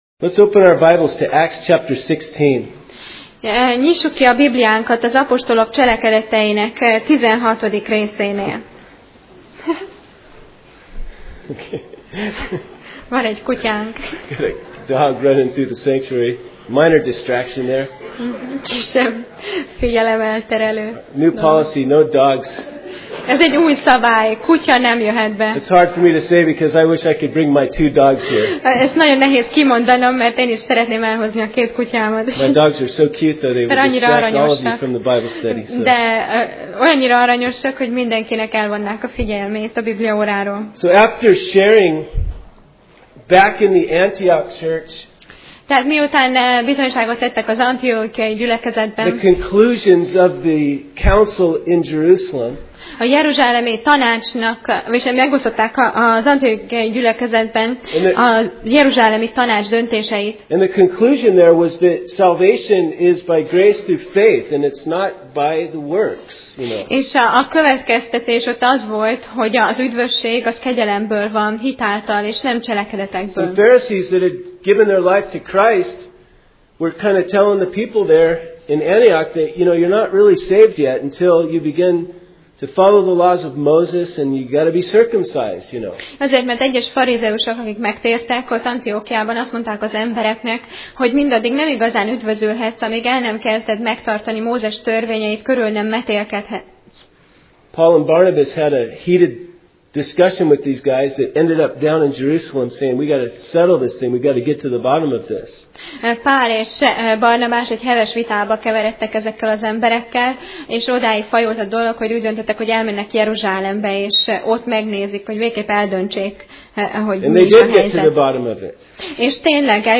Passage: Apcsel (Acts) 16:1-15 Alkalom: Vasárnap Reggel